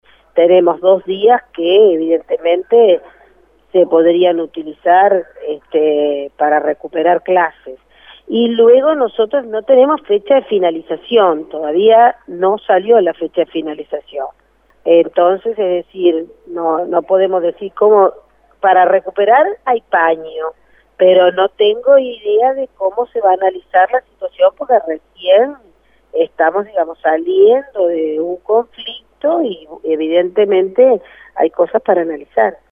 Palabras de Irupé Buzzetti
Por su parte, la directora de Primaria, Irupé Buzzetti, dijo a 810 Vivo que será el Consejo Directivo Central (Codicen) el que definirá cómo se recuperarán los días perdidos de clase.